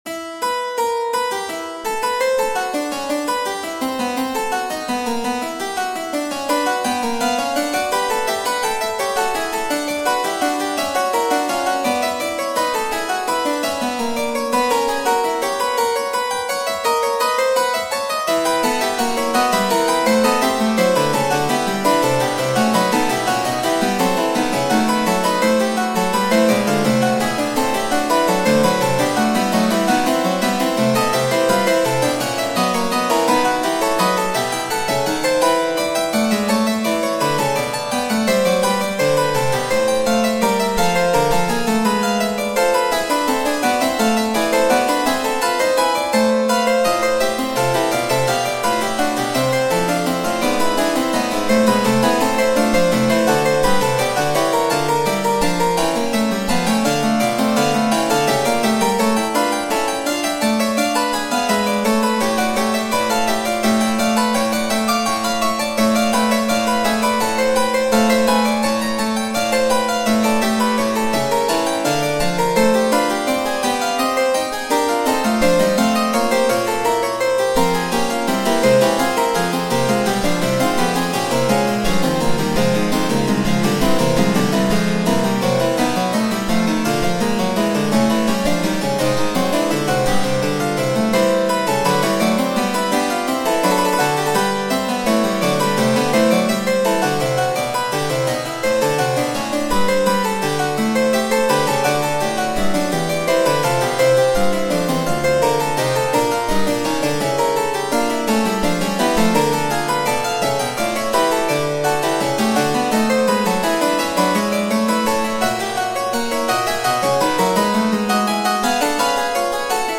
- Piano Music, Solo Keyboard - Young Composers Music Forum
Fugue in E minor No. 15.